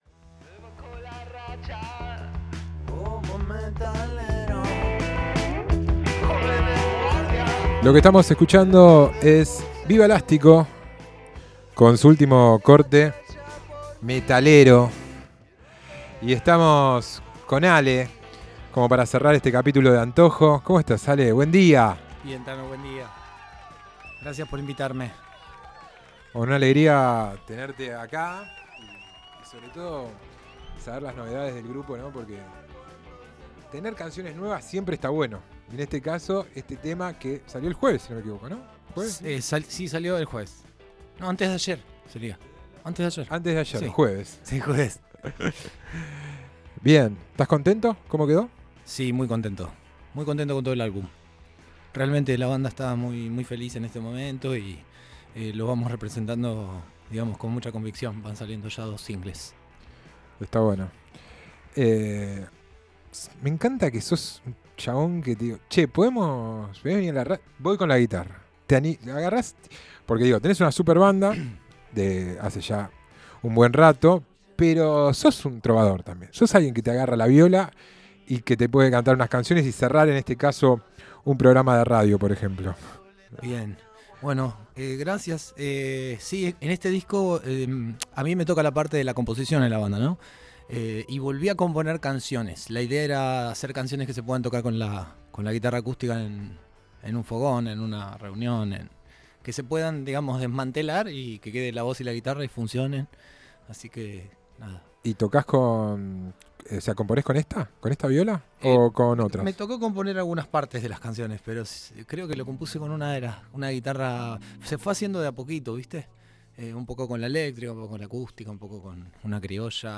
ENTREVISTA
tocó un par de temas acústicos